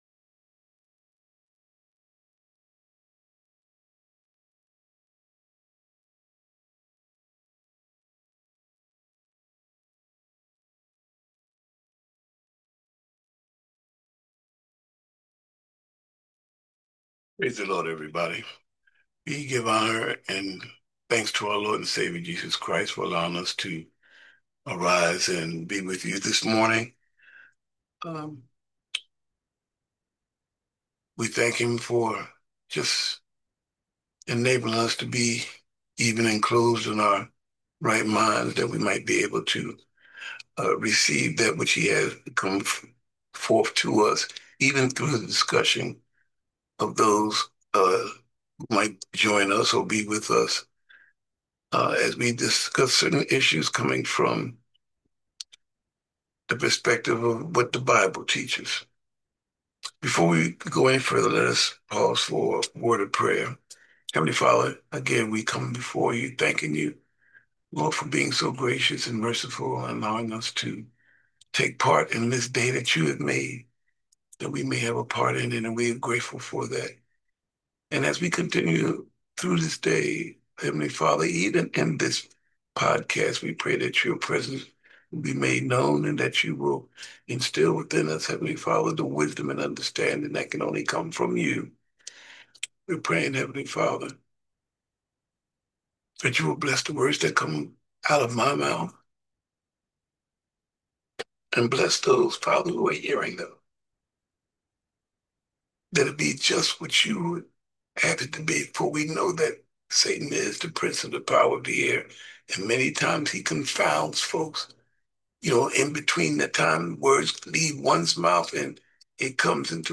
Remember, our ZOOM Podcast airs live on Tuesdays at 7:00am: